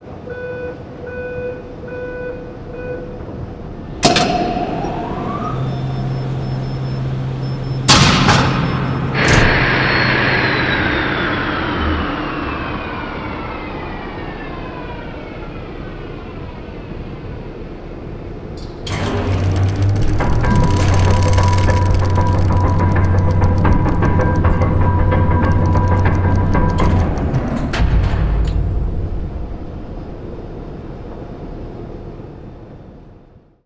Something unseals in the core chamber in which you're standing, and you hear and feel a corresponding rumble.
To your satisfaction, the ring clicks into place, stopping you from over-turning it.
core_ttv_ambience.mp3